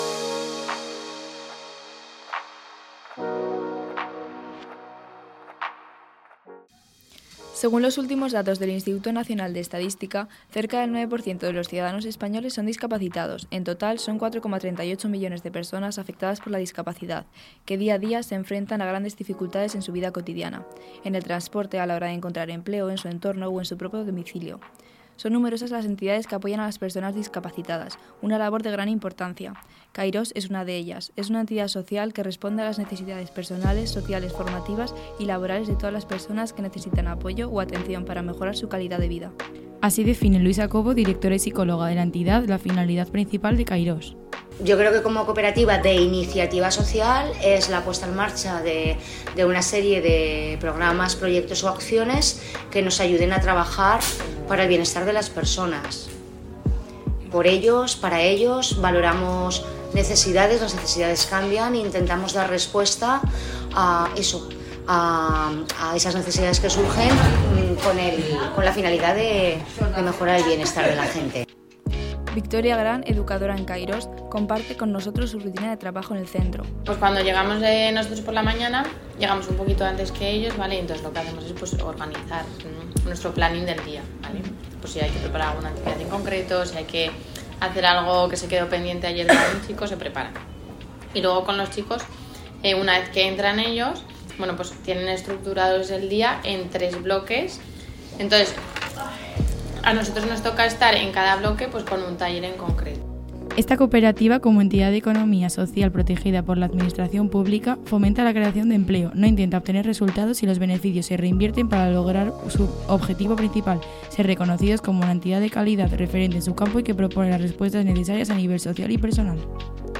8.-REPORTAJE_GRUPO-5-Kairos.mp3